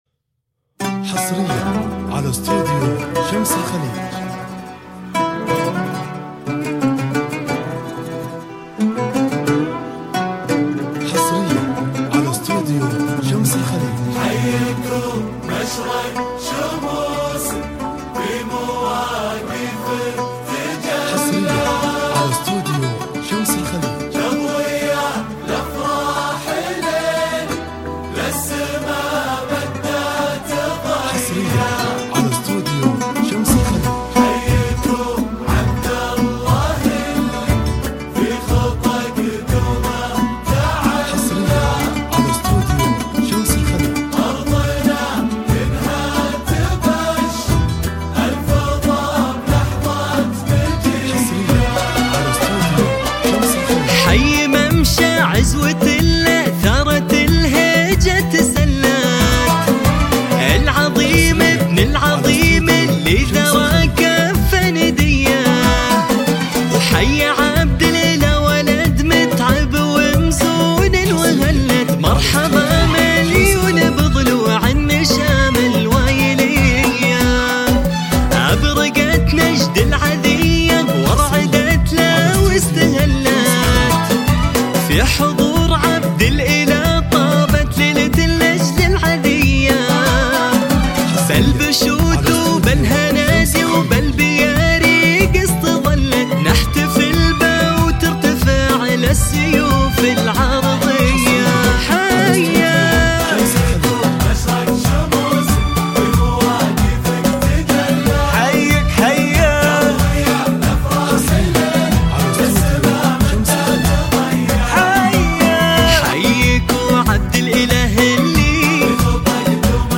زفات موسيقى